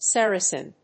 Sar・a・cen /sˈærəsn/
• / sˈærəsn(米国英語)